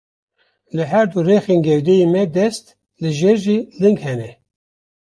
Pronunciado como (IPA)
/lɪŋɡ/